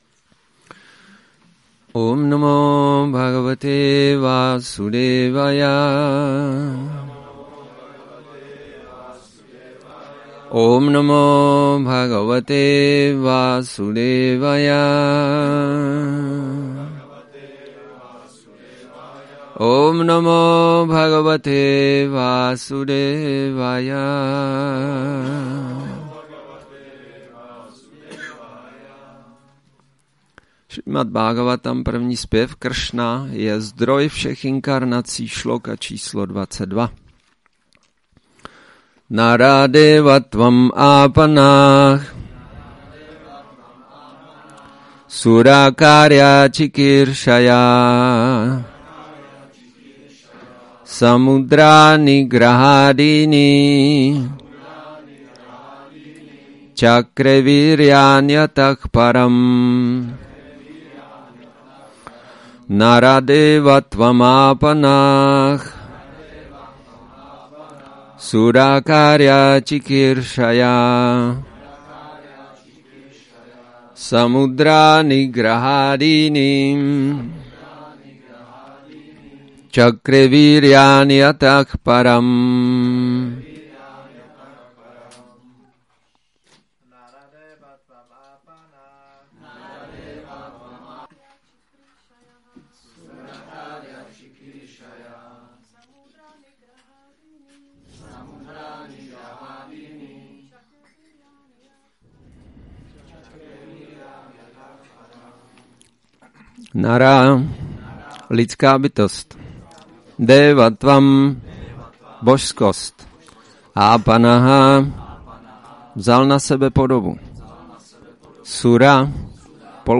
Přednáška SB-1.3.22 Rama Navami